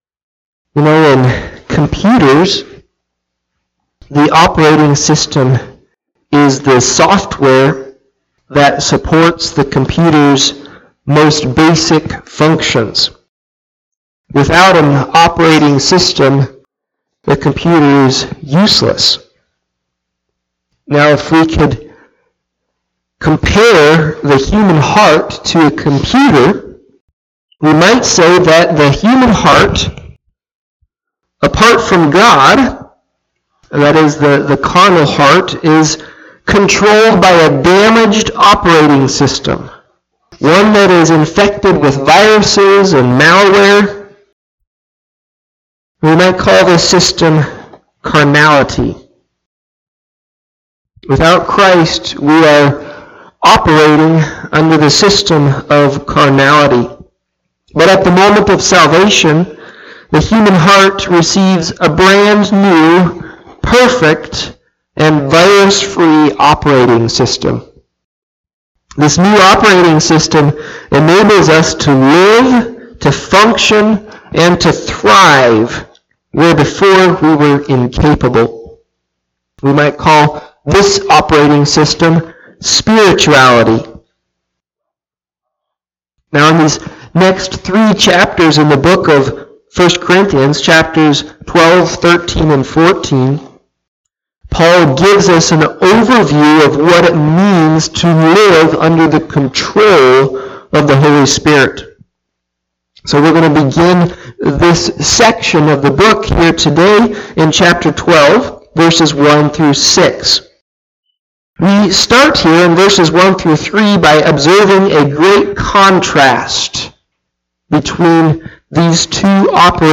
Listen to Audio of the sermon or Click Facebook live link above.